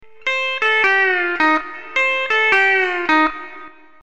Honky Tonk #2.  This lick is based on an F9 chord on the 6th fret, with both "A"
and "B" pedals down and E's lowered.